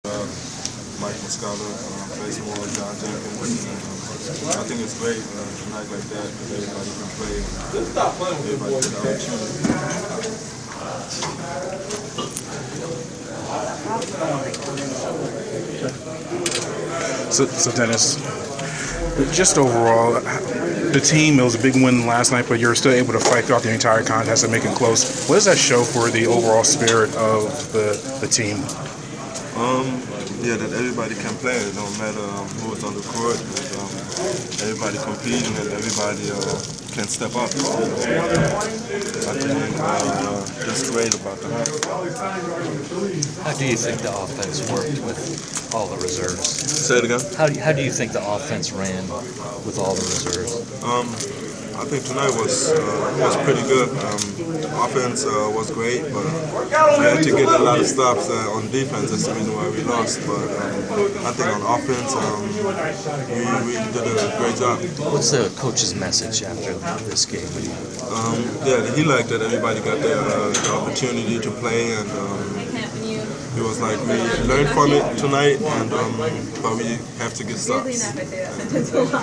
Inside the Inquirer: Postgame interview with Atlanta Hawks’ player Dennis Schroder (3/28/15)